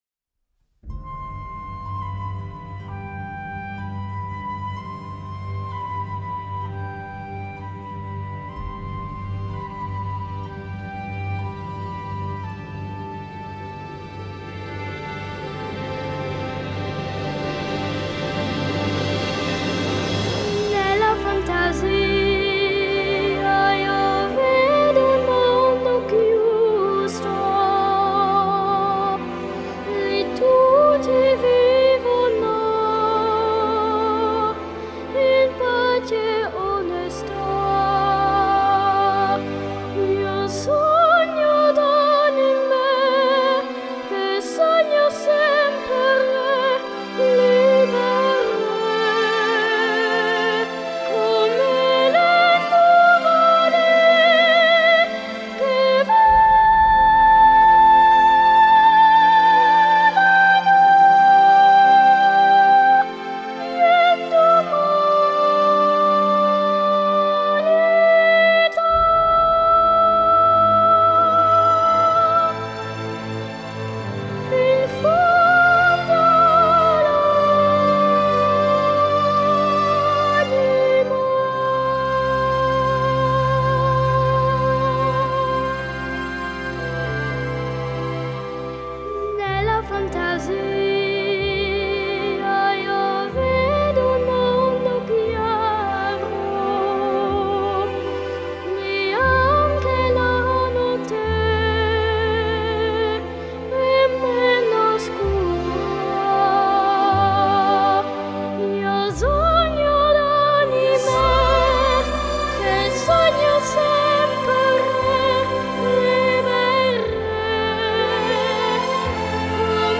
Genre: Classical, Opera